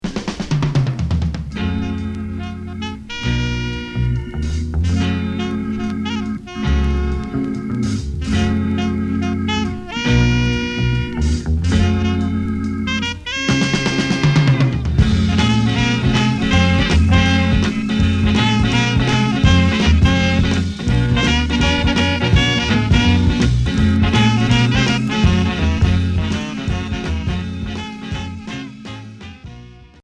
Groove pop